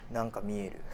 Aizu Dialect Database
Type: Statement
Final intonation: Falling
Location: Aizuwakamatsu/会津若松市
Sex: Male